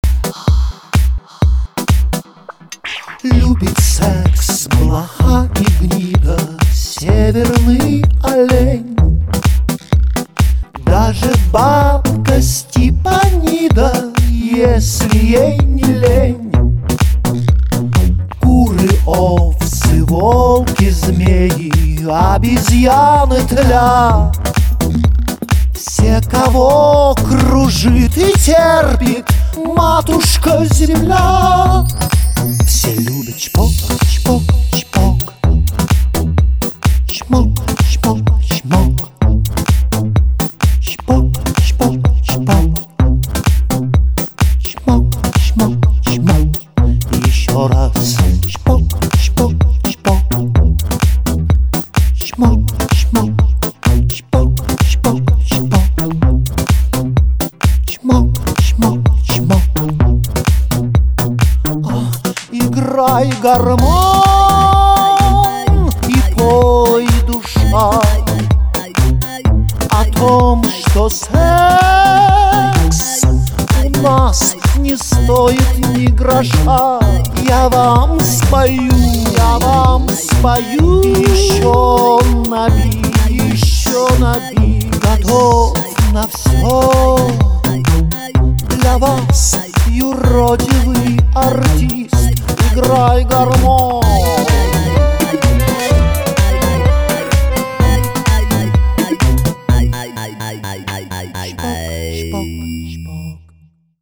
• Качество: 320, Stereo
поп
Electronic
романтичные